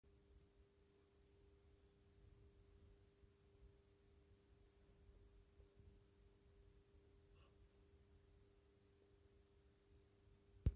J'ai fait le test, le bruit est présent dès la remise sous tension (après 30sec d'arrêt et pendant que les ailettes bouges).
J'ai essayé d'enregistrer le bruit mais il n'est pas très audible avec le micro du téléphone (le fichier est ci-joint). Il faut vraiment avoir l'oreille proche du haut parleur.
doublon 855724 clim.mp3
forum consulter ce sujet Problème sifflement électrique UI hors marche